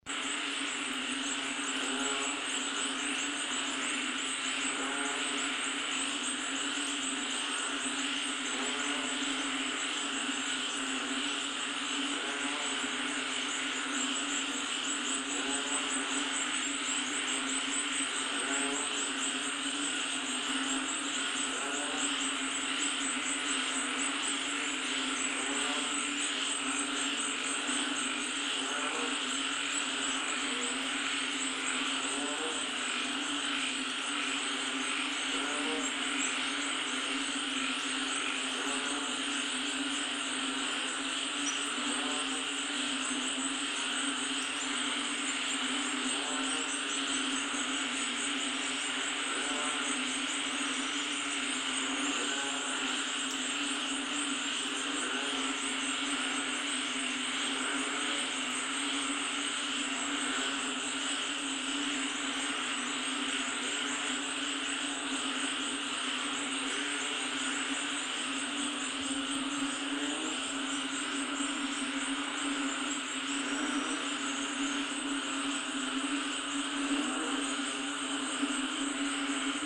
Unsere letzte Unterkunft lag zwischen einem Fluss und einem Tümpel. Kein Wunder also, dass sich eine Vielzahl unterschiedlicher Frösche hier wohl fühlen und den nächtlichen Regen zum Anlass nahmen, ein lautes und vielstimmiges Froschkonzert